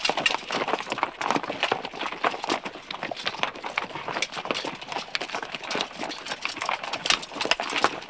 CavWalk1.wav